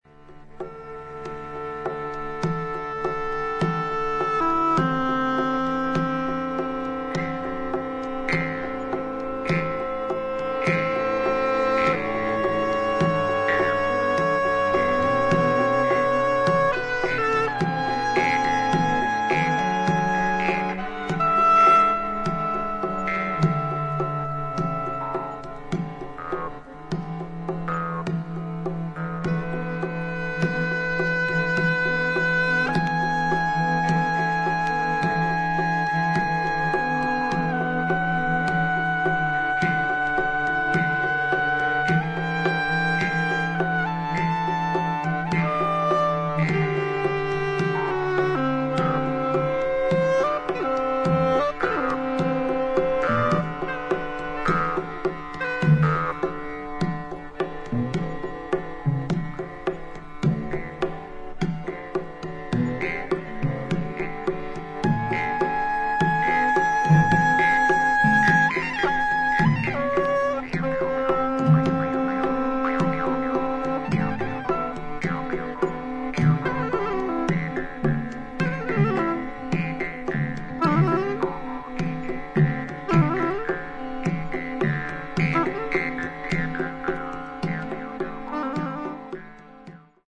インドのラーガとヨーロッパ伝統音楽にロックの前衛性を織り交ぜ